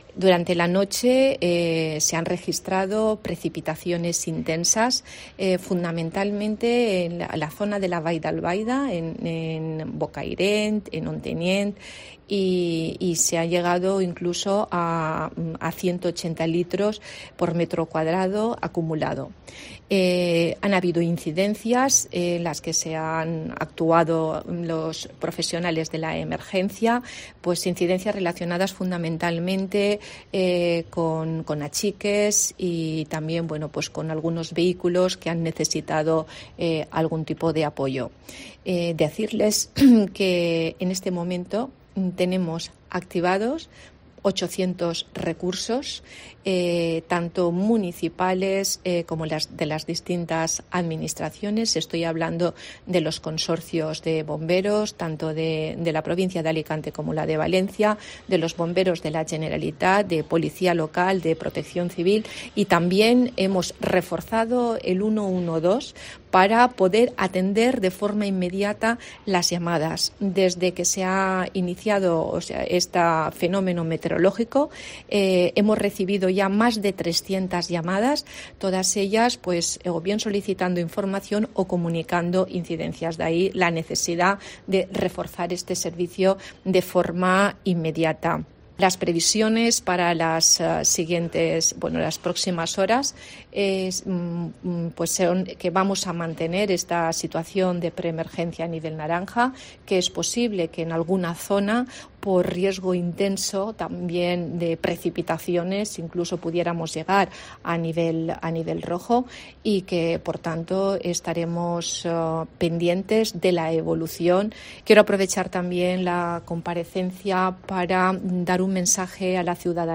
La consellera de Interior, Gabriela Bravo, cuenta la última hora del temporal